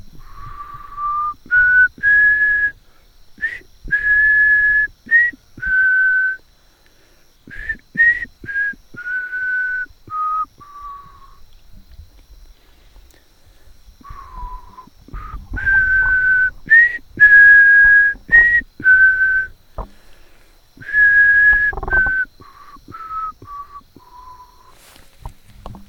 휘파람.ogg